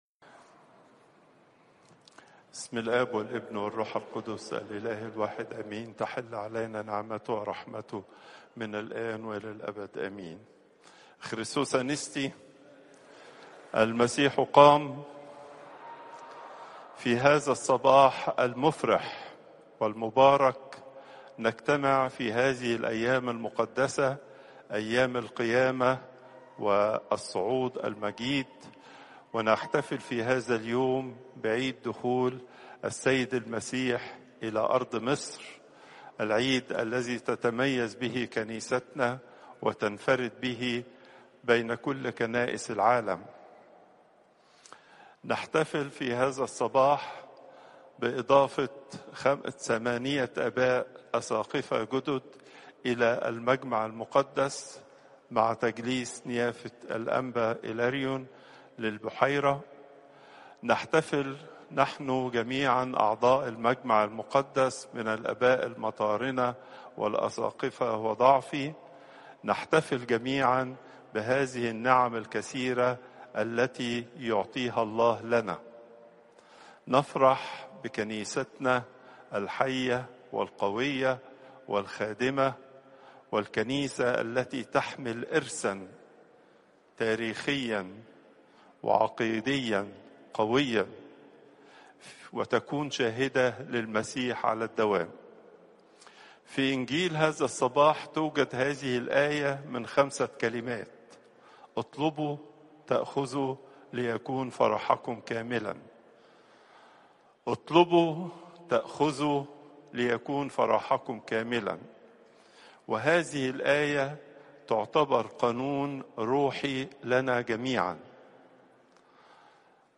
Pope Tawdroes II Weekly Lecture